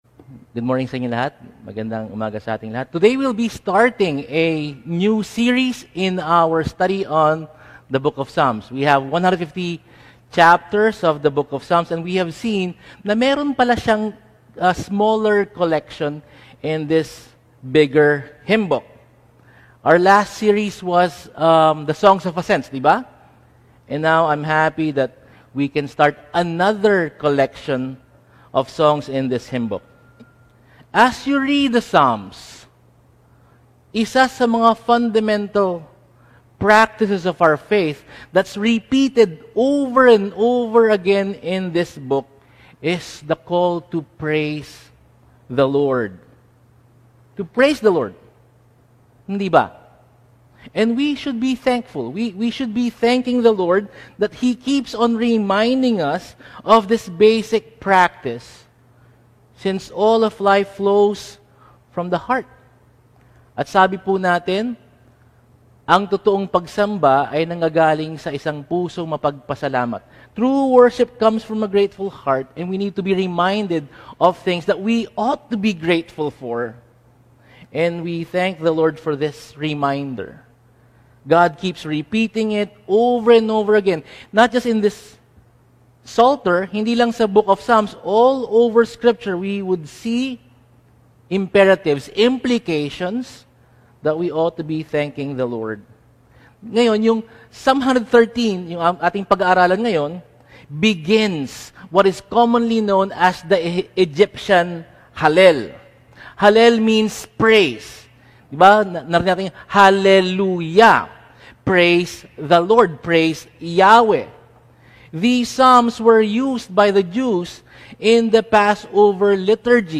Sunday Sermon Outline